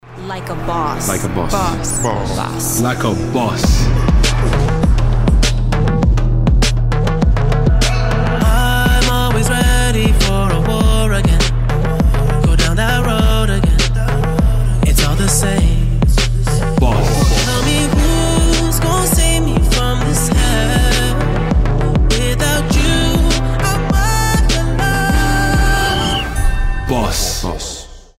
• Качество: 320, Stereo
Electronic
pop rap